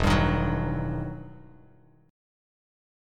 EmM9 chord